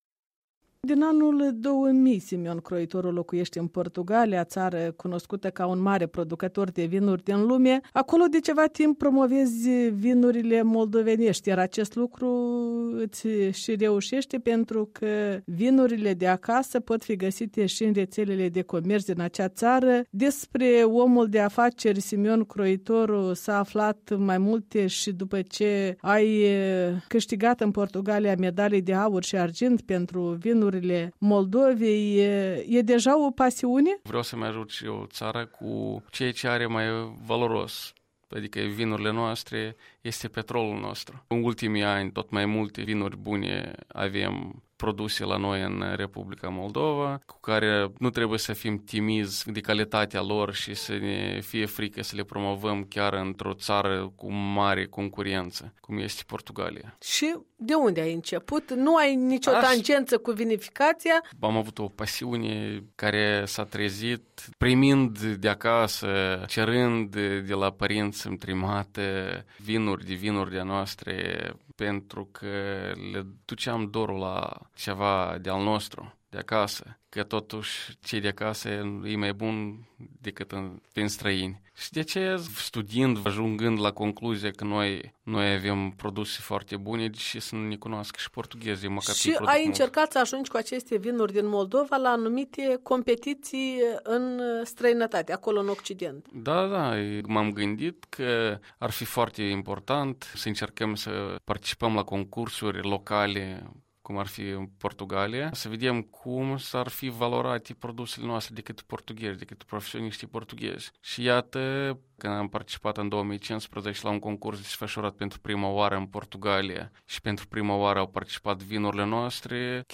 Un interviu cu un om de afaceri moldovean stabilit în Portugalia.